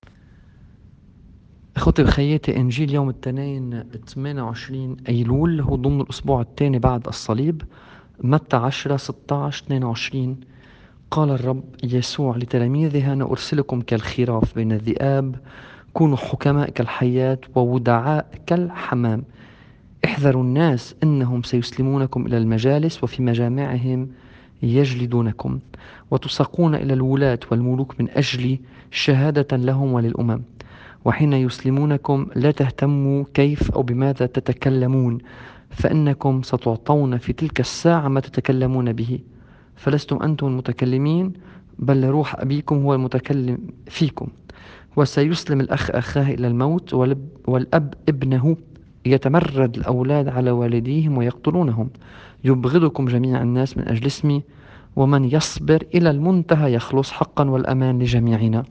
الإنجيل بحسب التقويم الماروني :